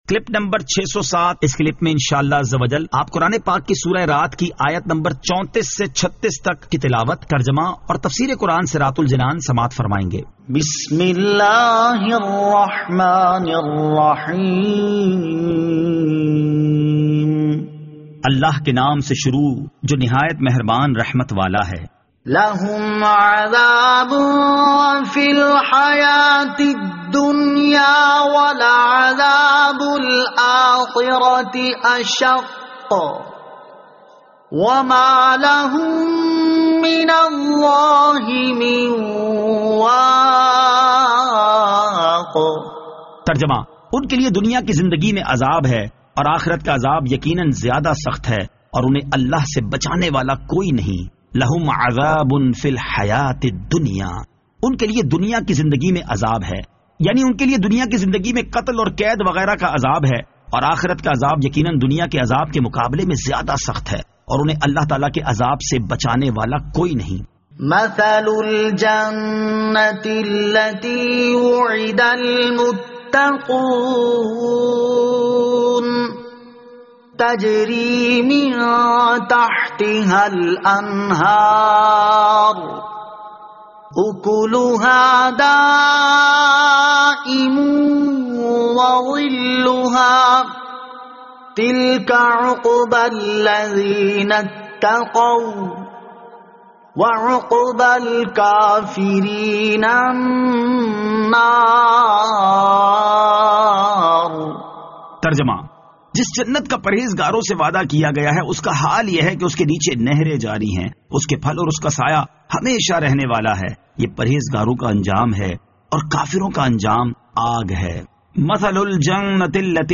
Surah Ar-Rad Ayat 34 To 36 Tilawat , Tarjama , Tafseer